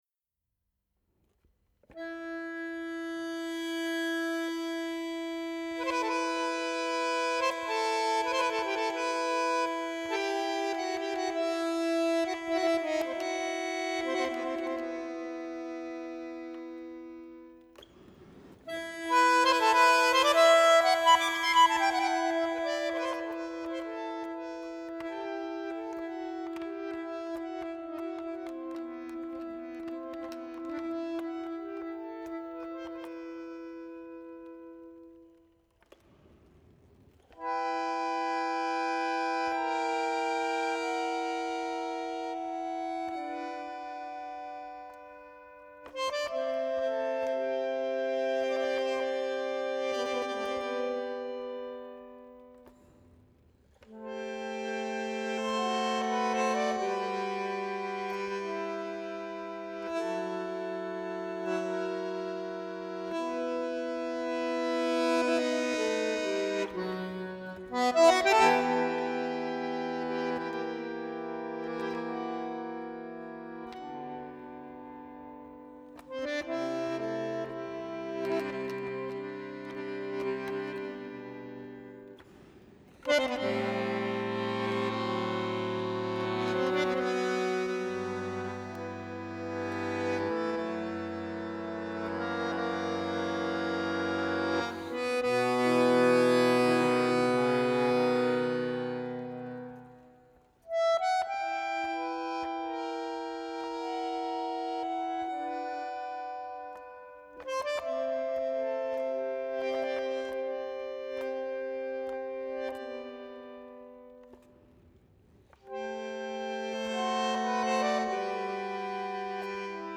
Жанр: Jazz.